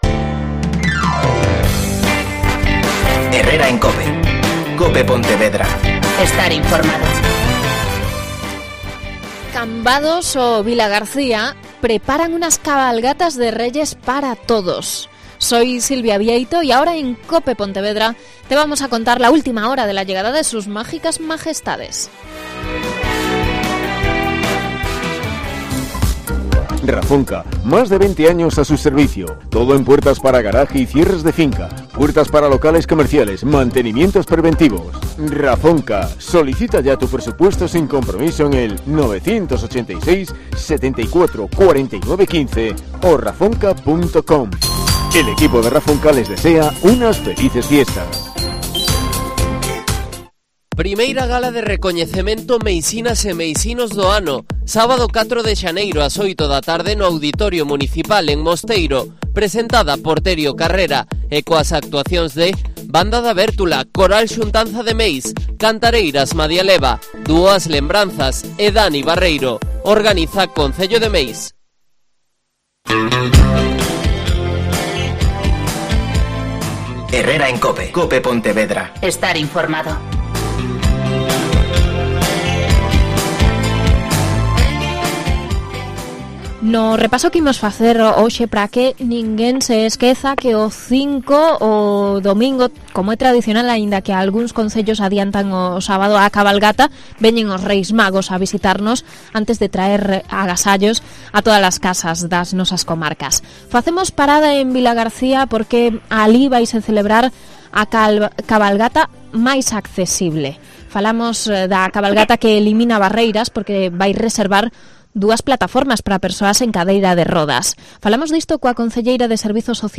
Tania García. Concejala de Bienestar Social en el Ayuntamiento de Vilagarcía.